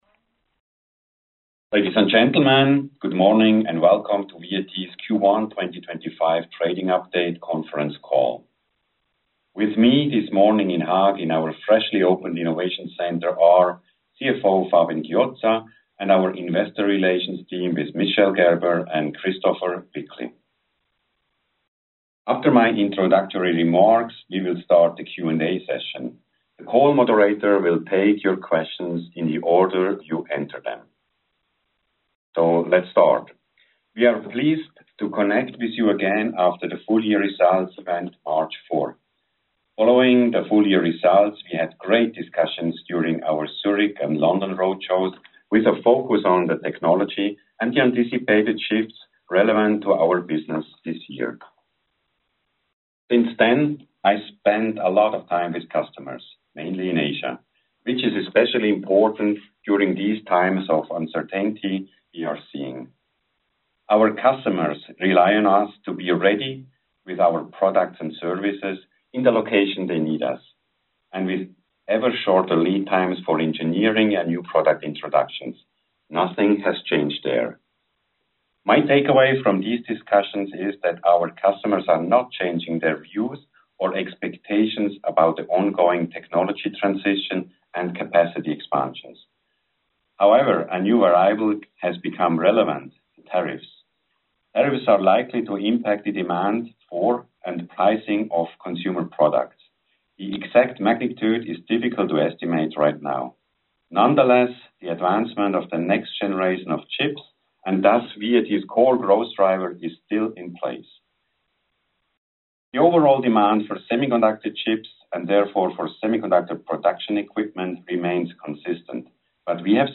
Conference call – VAT Q1 2026 trading update
vat-q1-2025-trading-update-conference-call.mp3